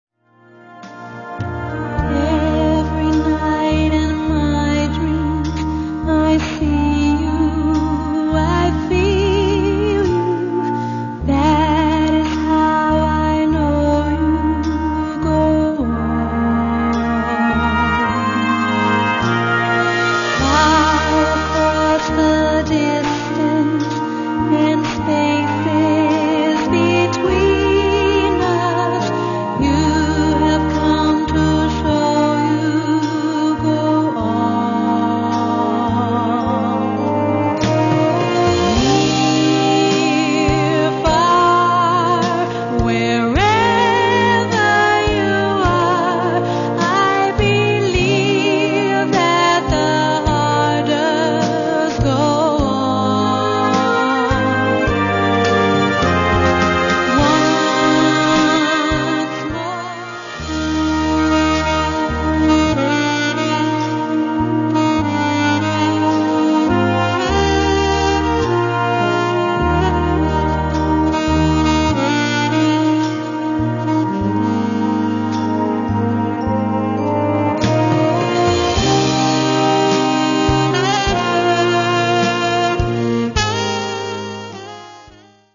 Gattung: Solo für diverse Instumente und Blasorchester
Besetzung: Blasorchester